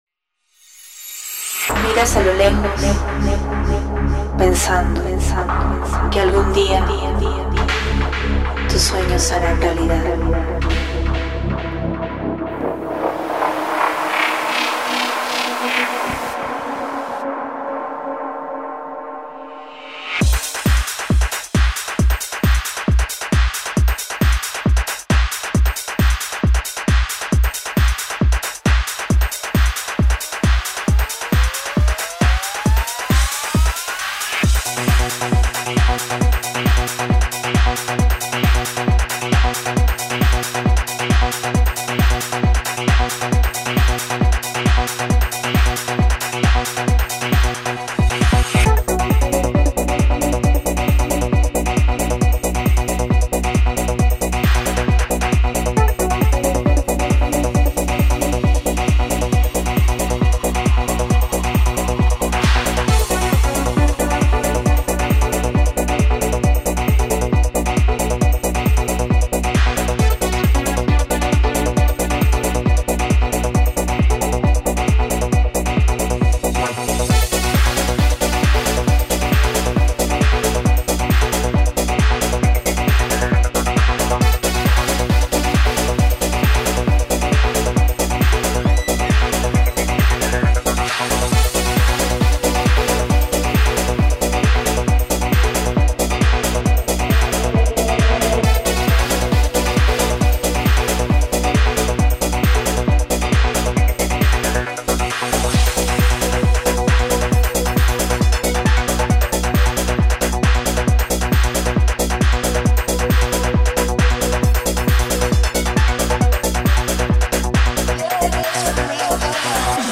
Интересуют в основном бочки (быстрые, но как бы с естественным панчем), тарелки, эффекты для транса. Транс образца конца 90х, начала 2000х.